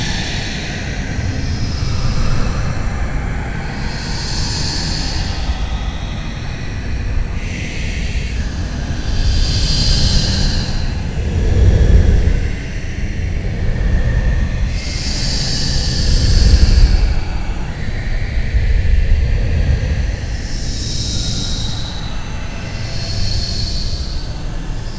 SOUNDS: Make sure echoes is mono, bump volume to ease compression
echoes.wav